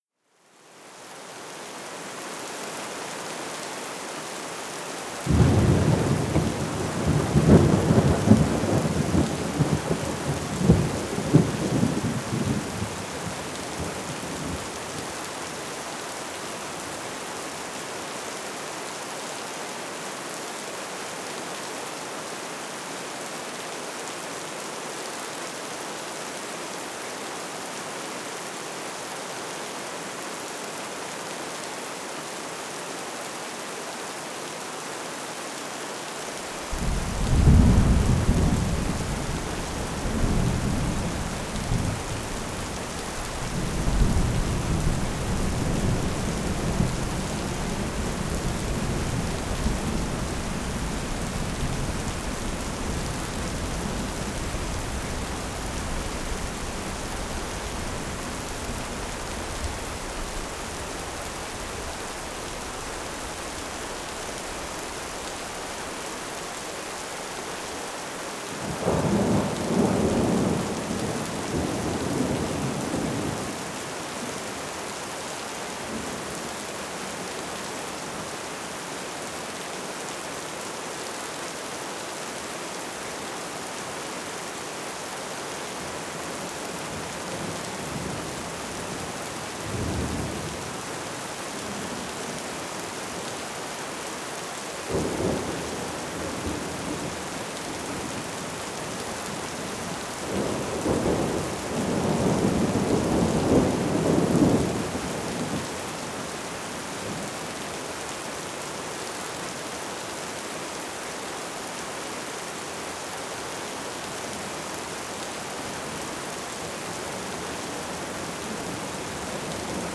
Pluie apaisante et tonnerre lointain – Enregistrement de sons de la nature – Pour la méditation, la détente et le sommeil – Le bruit blanc parfait de la nature
0% Extrait gratuit Pluie apaisante et tonnerre lointain – Enregistrement de sons de la nature – Pour la méditation, la détente et le sommeil – Le bruit blanc parfait de la nature Laissez-vous bercer, détendez-vous profondément ou endormez-vous paisiblement au son relaxant de la pluie et du tonnerre lointain.